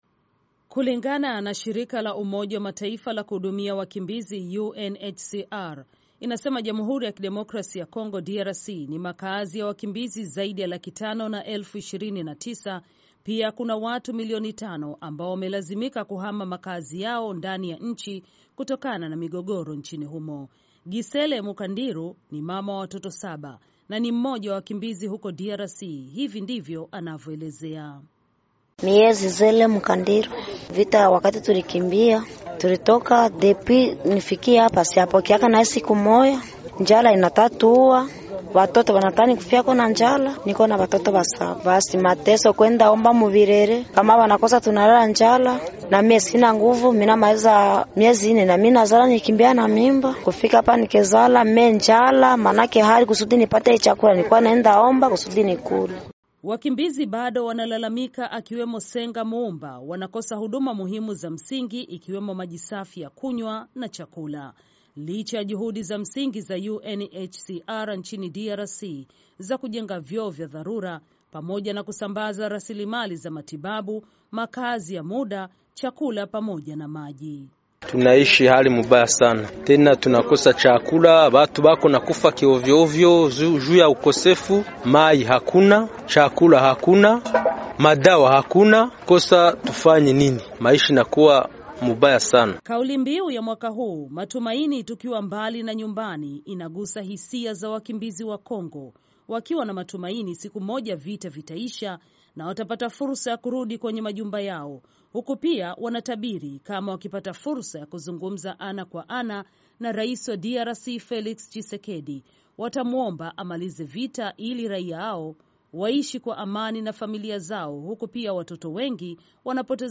ripoti ifuatayo